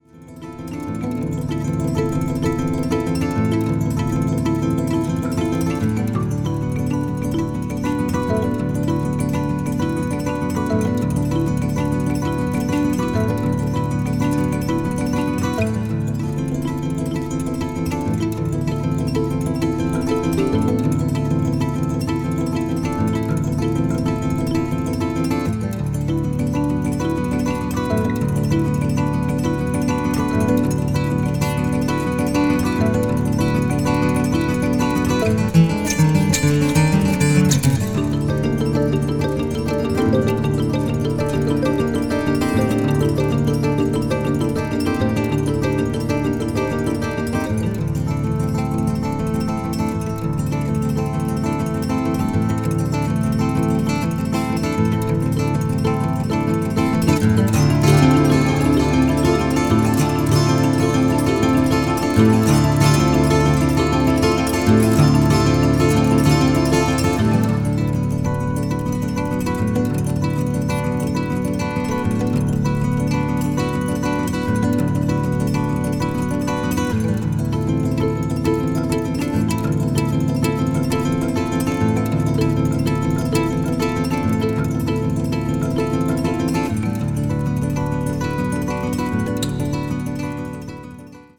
media : EX/EX(some slightly noises.)
contemporary jazz   guitar duo   new age